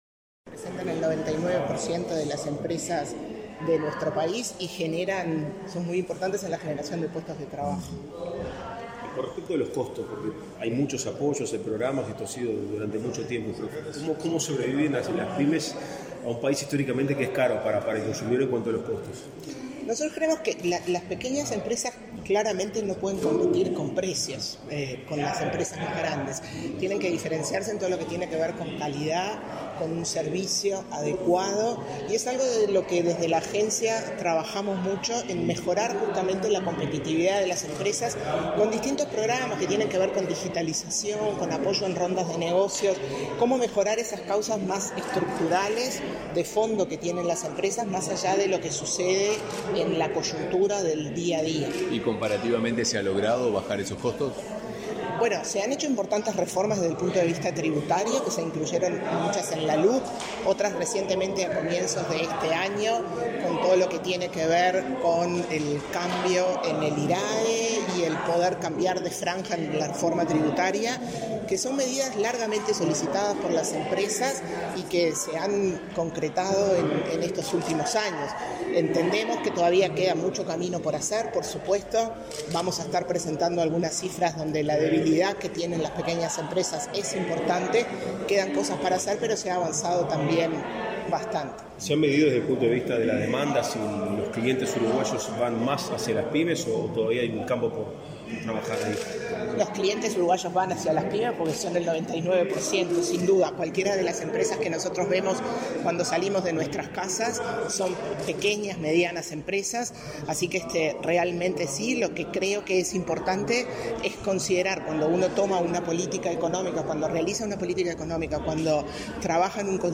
Declaraciones a la prensa de la presidenta de la ANDE, Carmen Sánchez
Además, celebró la entrega del Premio a la Mipyme del Año 2023. En la oportunidad, la presidenta de la referida institución, Carmen Sánchez, realizó declaraciones a la prensa.